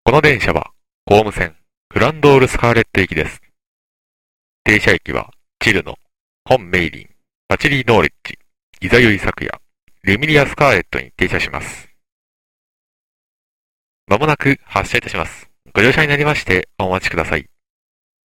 紅霧線アナウンス -